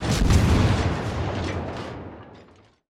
WULA_Basttleship_Shootingsound_M.wav